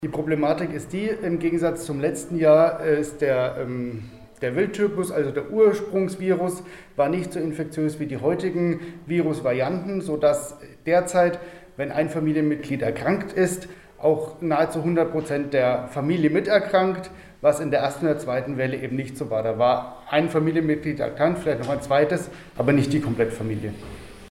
Besuch im Gesundheitsamt Schweinfurt- Alle Interviews und Videos zum Nachhören - PRIMATON